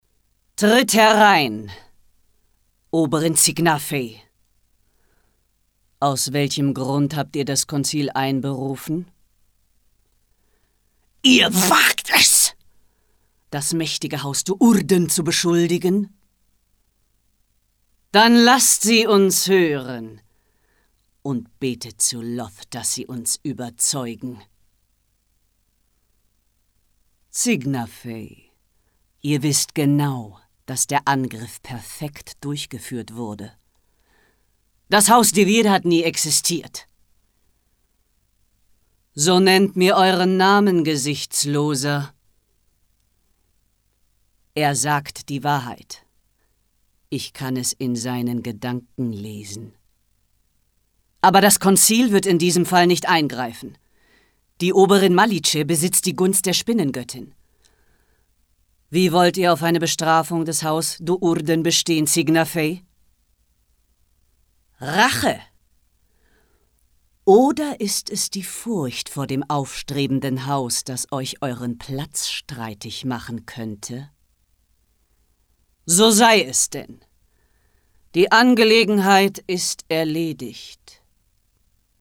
Literatur
Dark Fantasy_herrisch, böse